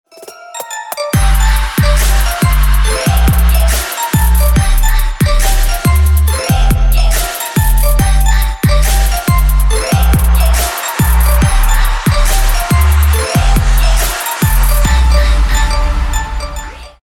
Categoria Natale